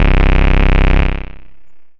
奇怪的无人机 " 奇怪的无人机4
描述：刺耳但令人愉快的嘶嘶脉冲声。
标签： 雄蜂 效果 处理 脉搏
声道立体声